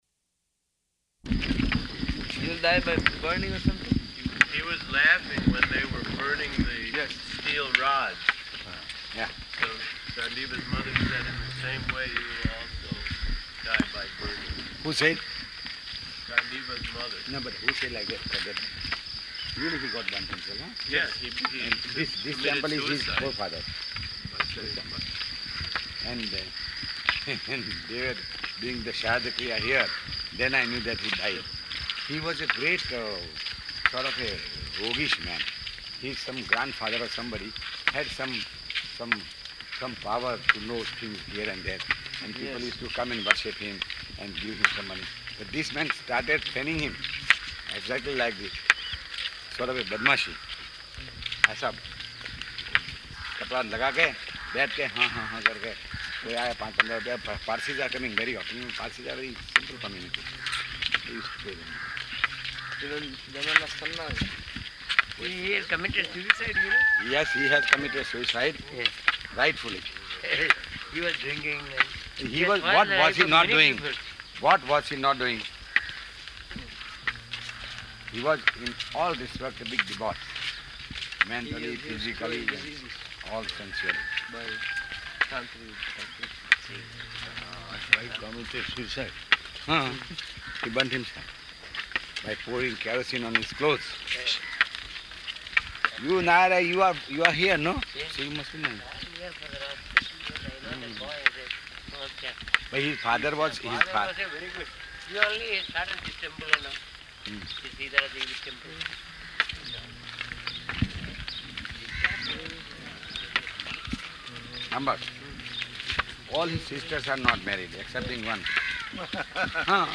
November 12th 1975 Location: Bombay Audio file